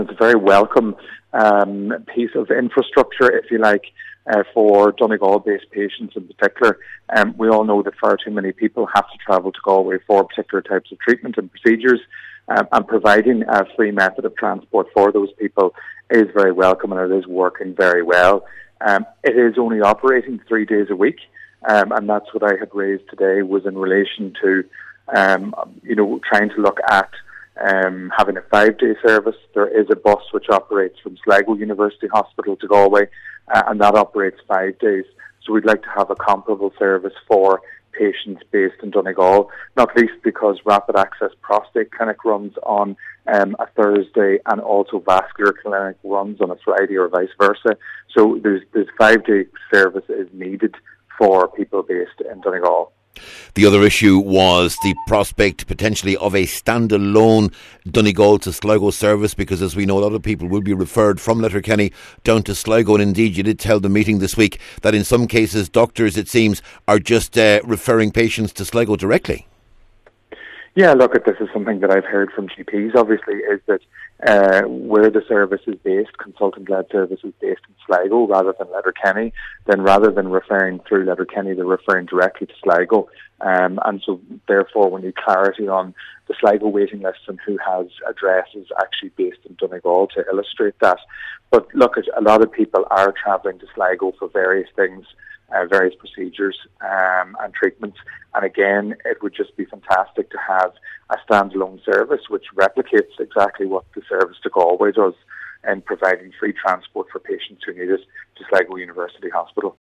He says the service started off as a pilot funded by the friends of the hospital, but demand has increased, and an expansion is needed: